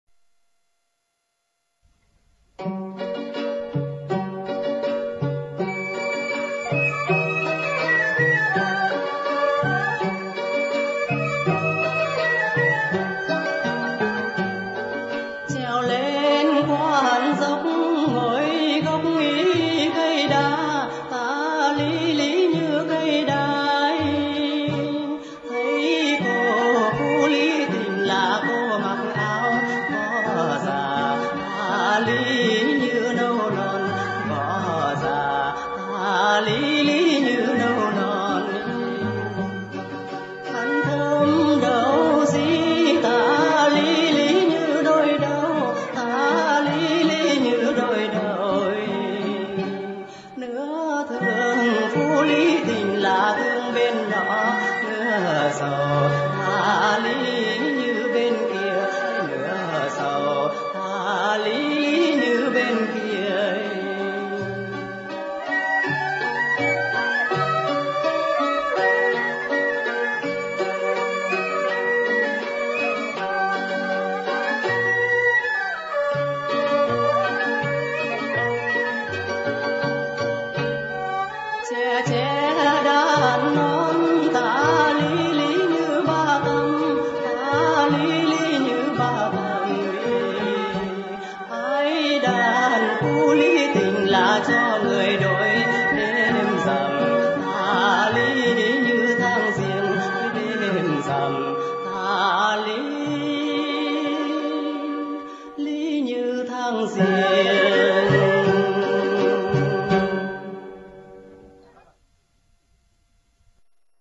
quan họ Sáng tác